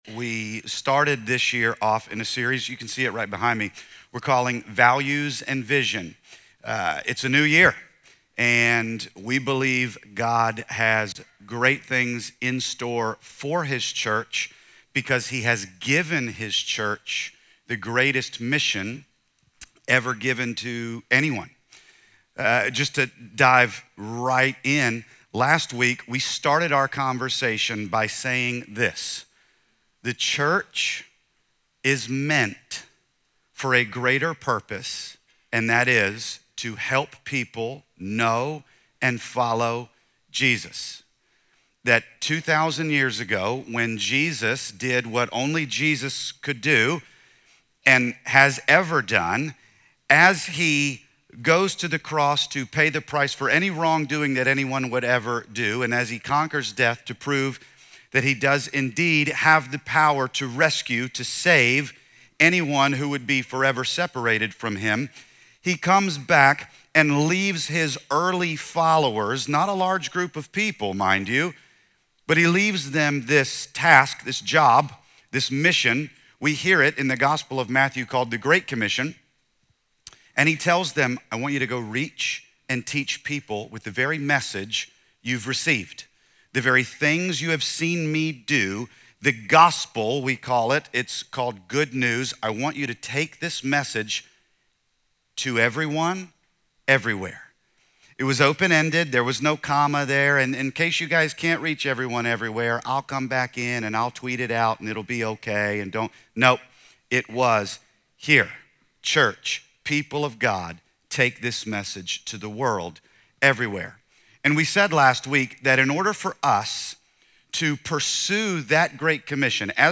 “Values Over Vision” explores why foundations matter more than grand goals. Rather than starting with a vision statement, this message emphasizes grounding everything in core values first—those guiding principles that shape culture, decisions, and sustainable growth.